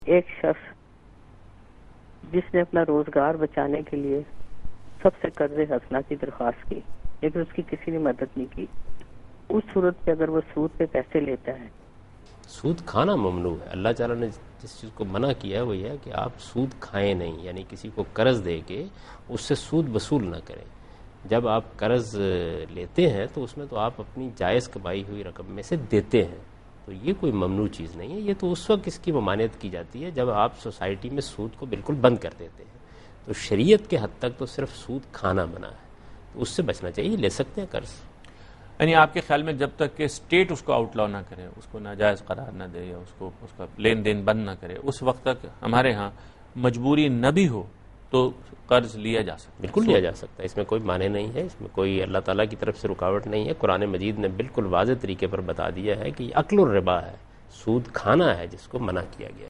Category: TV Programs / Dunya News / Deen-o-Daanish /